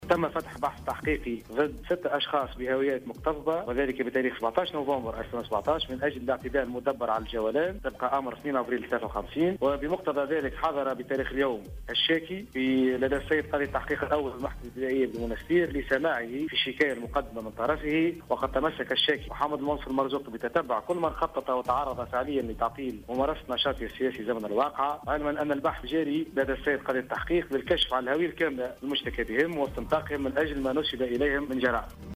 قال المساعد الأول لوكيل الجمهورية والناطق الرسمي باسم محاكم المنستير، فريد بن جحا في تصريح لـ "الجوهرة أف أم" إنه تم اليوم الاستماع للرئيس السابق محمد المنصف المرزوقي، وذلك على خلفية الشكاية التي تقدّم بها على خلفية منعه من دخول مقر إحدى الإذاعات الخاصة بالمنستير.